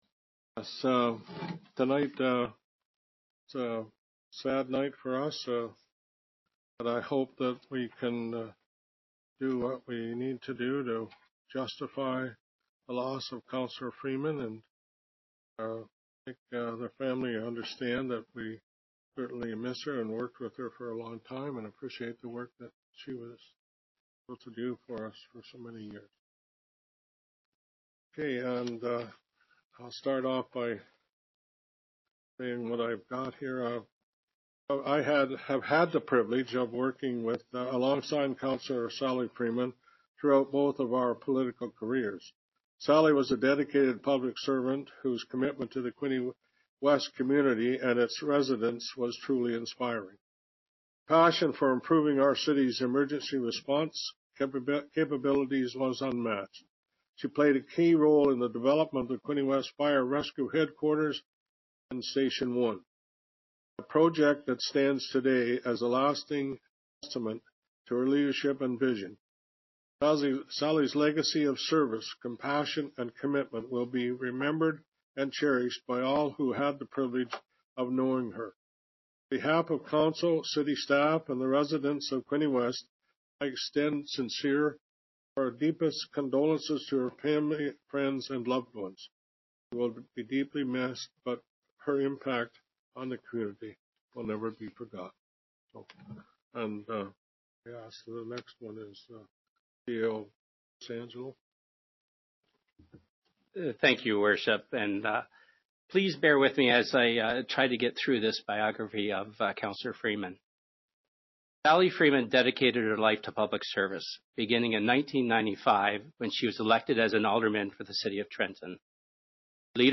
Tributes and condolences were shared for the late Sally Freeman at the beginning of the Quinte West City Council meeting on Wednesday.